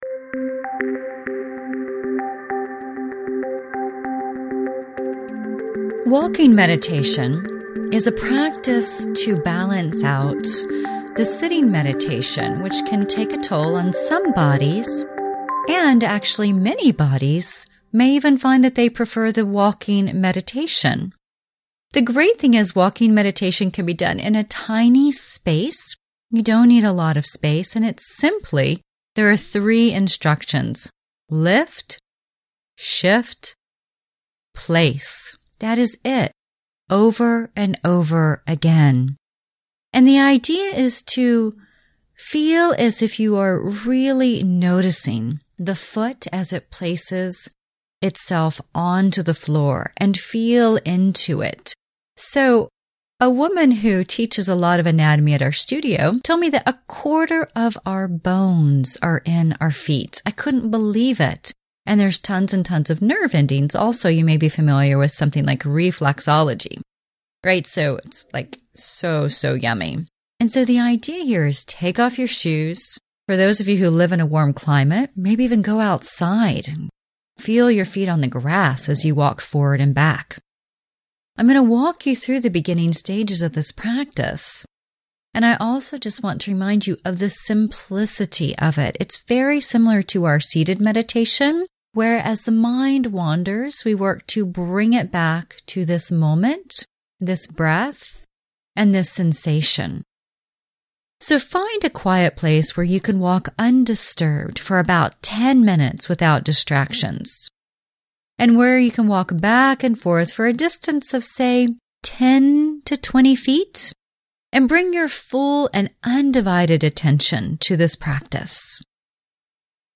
walking-meditation.mp3